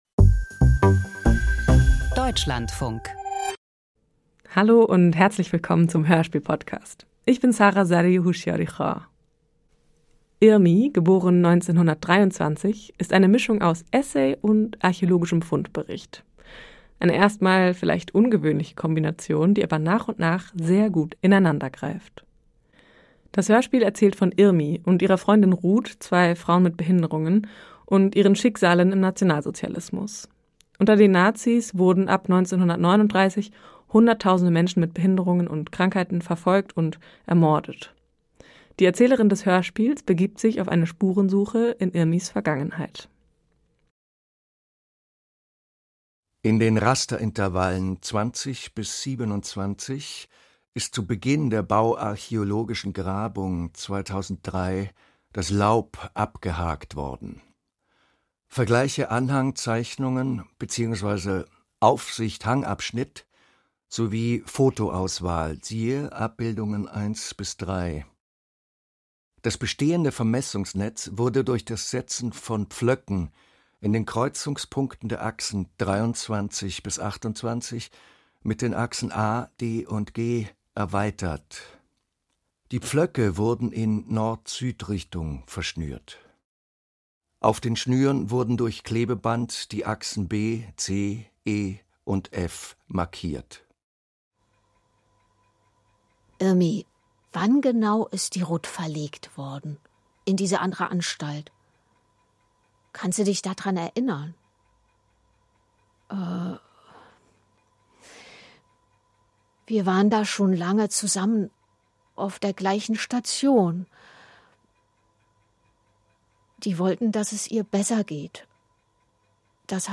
Dokufiktionales Hörspiel